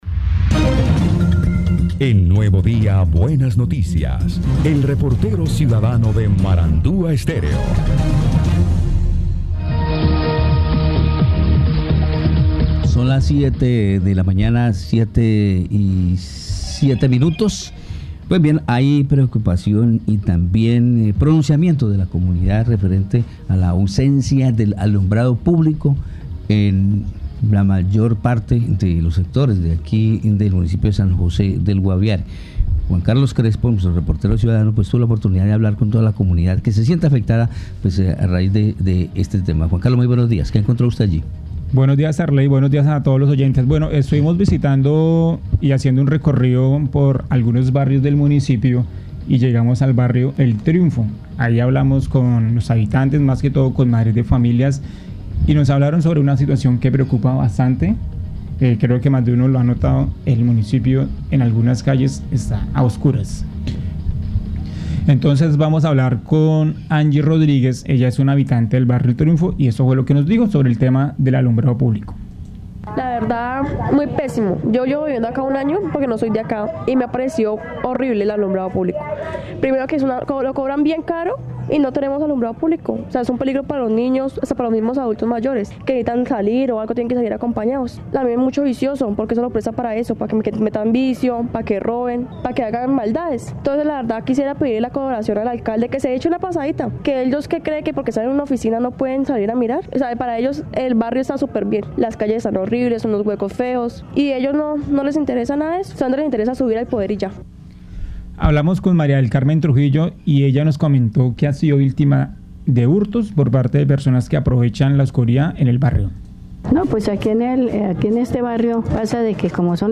El Reportero Ciudadano de Marandua Stereo recorrió las calles de la capital del Guaviare y encontró que muchas vías urbanas se encuentran a oscuras.